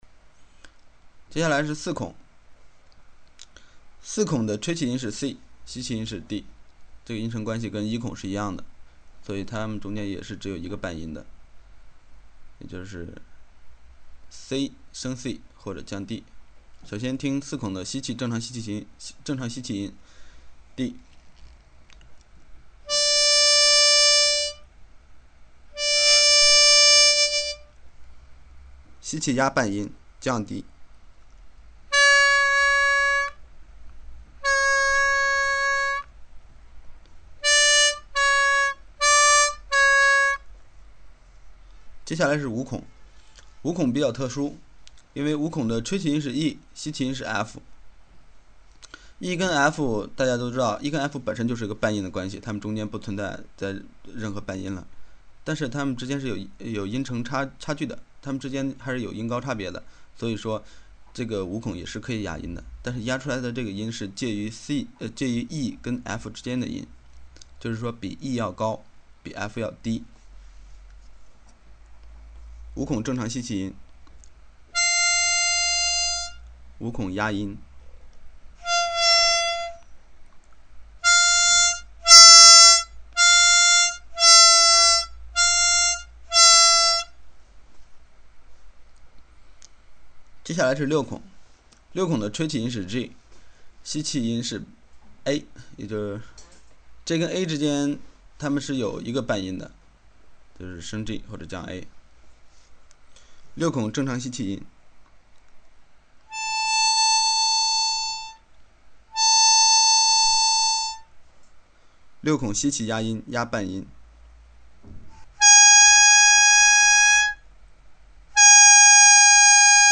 四到六孔压音标准音频.
0193-四到六孔压音标准音频.mp3